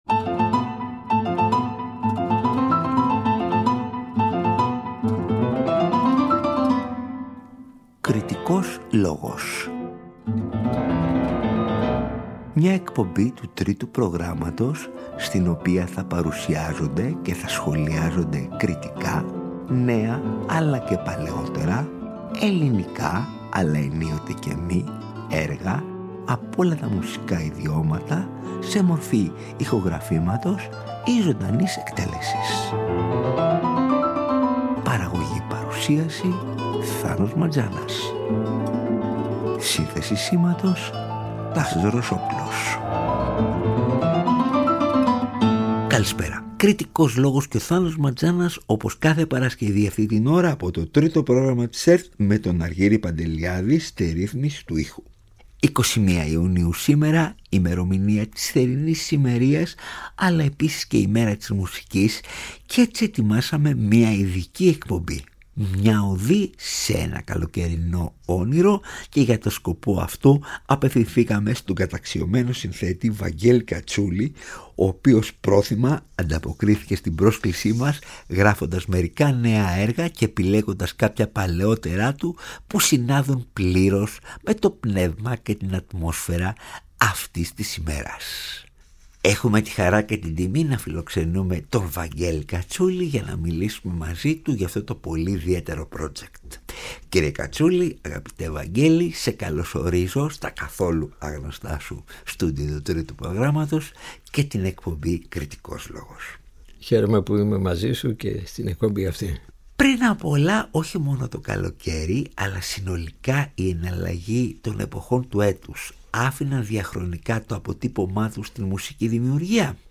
Στην εκπομπή ακούγονται συνολικά εννέα οργανικές συνθέσεις εκ των οποίων τέσσερις νέες . Πρόκειται για τα έργα: “L’ Estate” για keyboards και ηλεκτρονικά , “Still Lake” για φωνητικά, keyboards και ηλεκτρονικά , “Rozanna Rosas Amat” για keyboards και ηλεκτρονικά και “Summercrime” για jazz πιάνο τριο .
για γυναικεία φωνή, σαξόφωνο, συνθεσάιζερ , ηλεκτρική κιθάρα, κοντραμπάσο και κρουστά